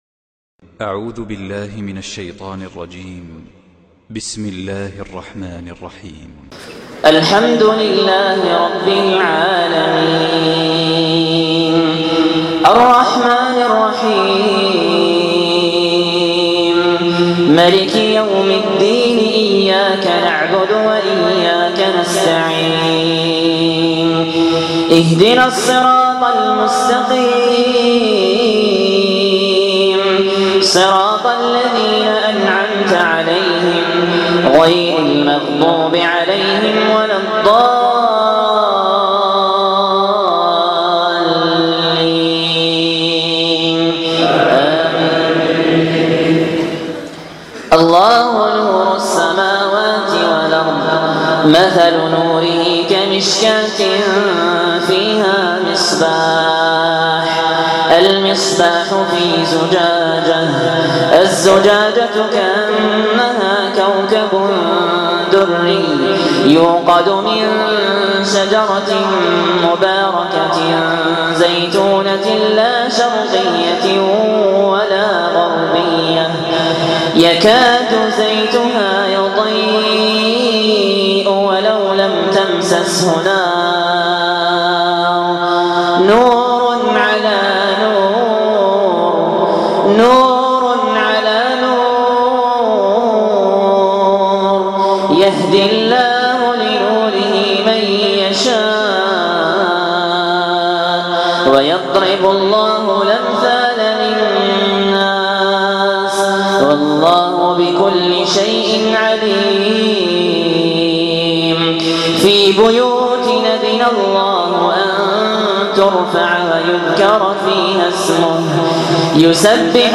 تلاوات من الصلوات الجهرية